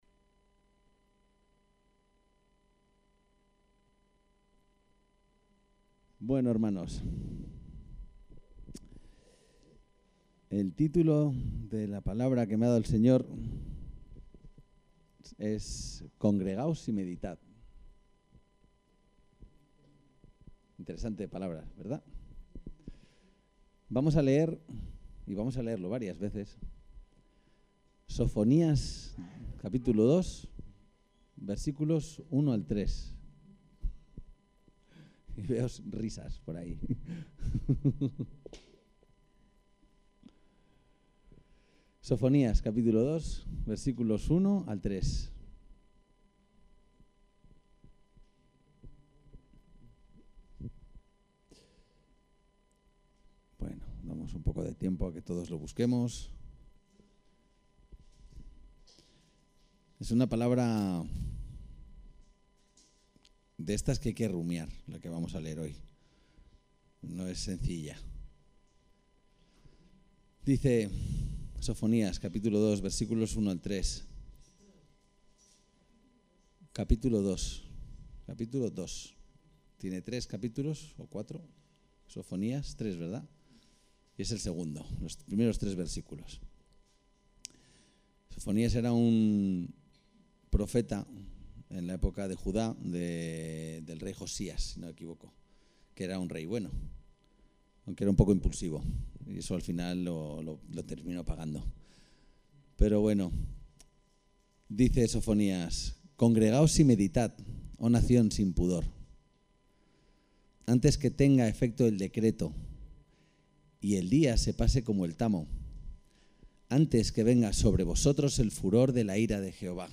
El texto de la predicación se puede descargar aquí: Congregaos y meditad